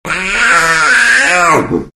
Звуки черной пантеры
Звук с рыком пантеры